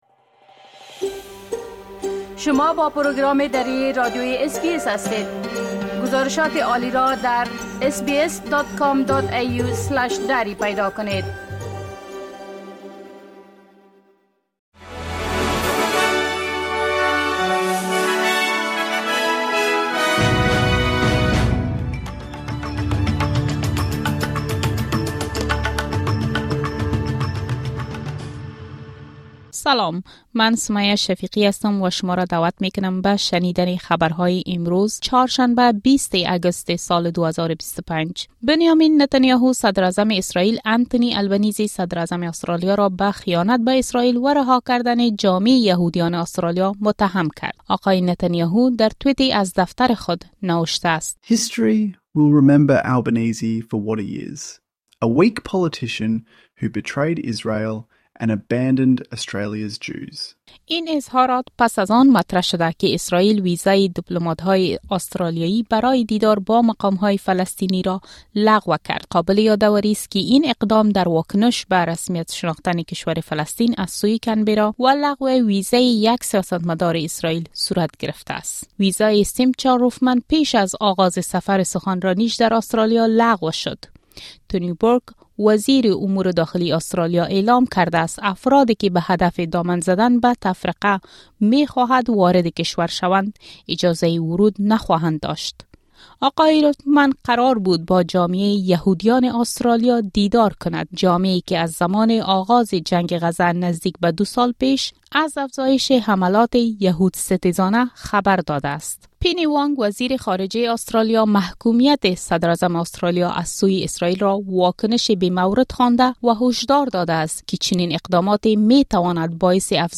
خلاصۀ مهمترين خبرهای روز از بخش درى راديوى اس‌بى‌اس